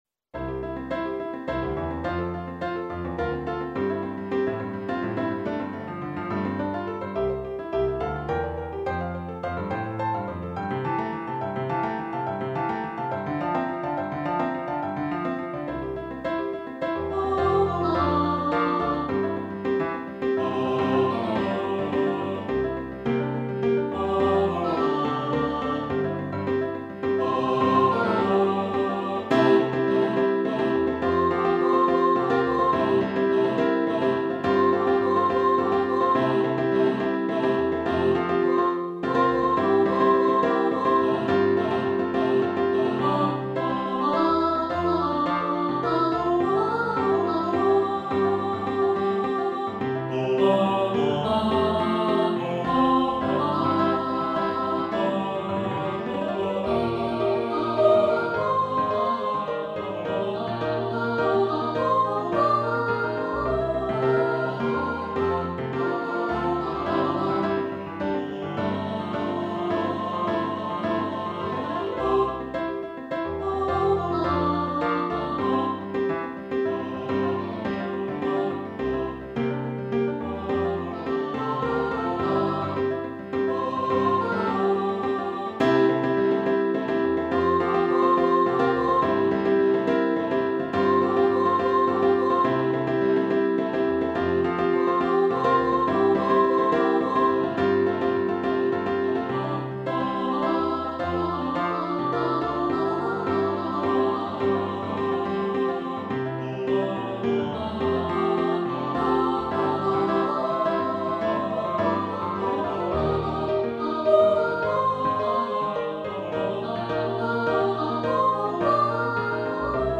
soprano, baritone, choir, piano
Electronically Generated